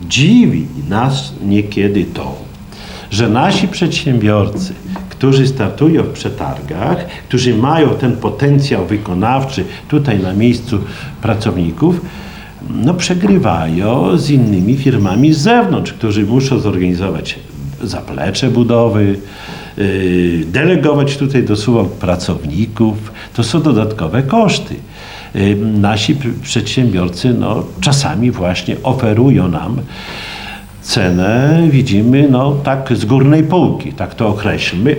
konferencja2.mp3